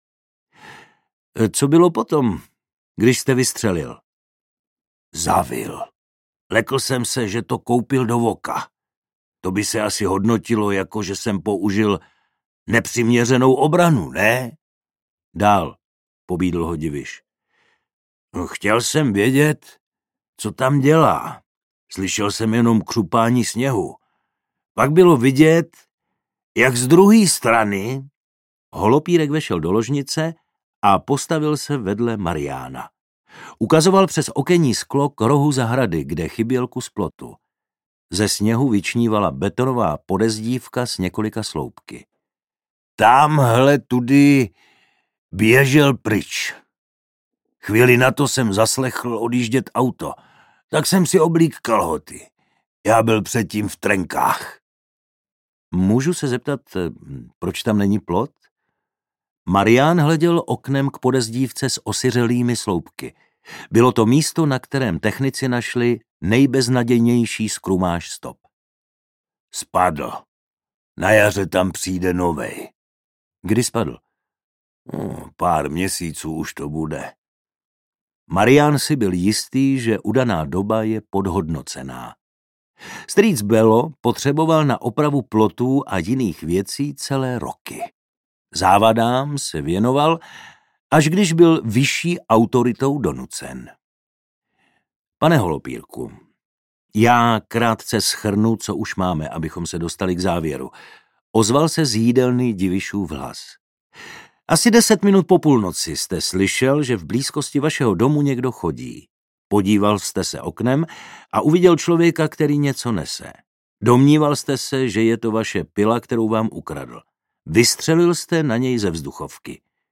Audio knihaDívky nalehko
Ukázka z knihy